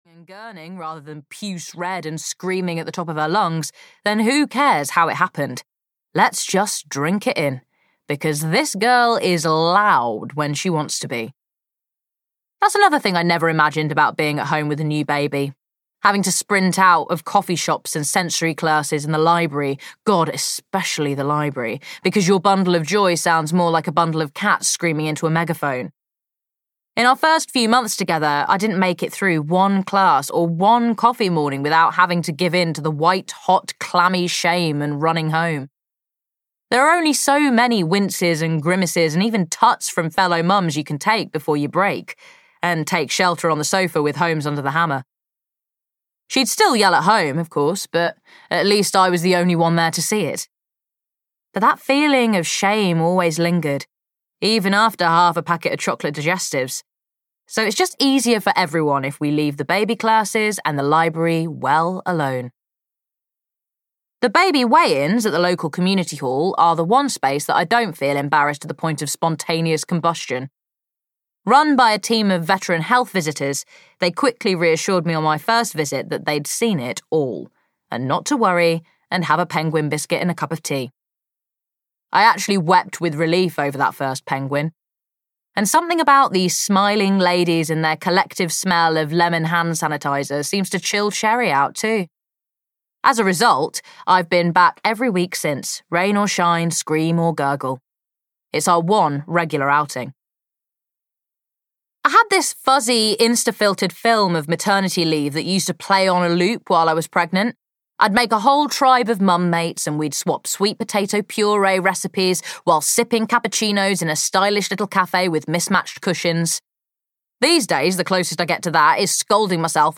Confessions of a First–Time Mum (EN) audiokniha
Ukázka z knihy